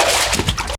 sfx_step_water_l.wav